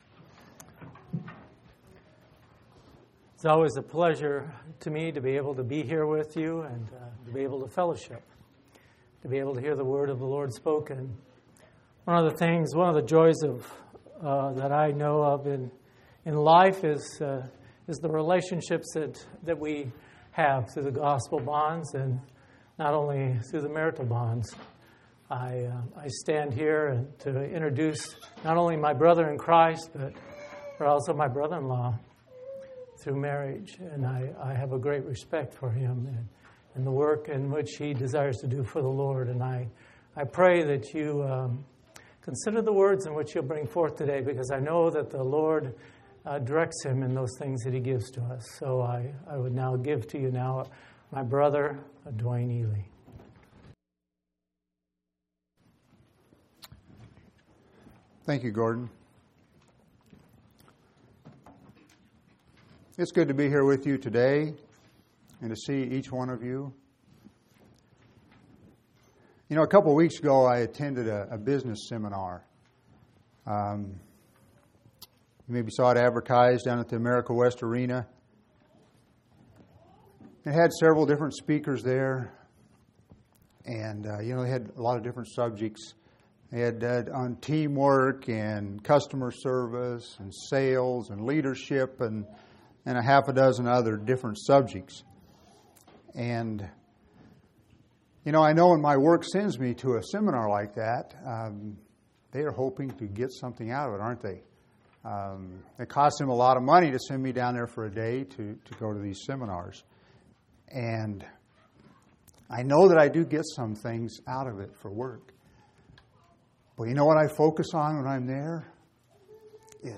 2/27/2005 Location: Phoenix Local Event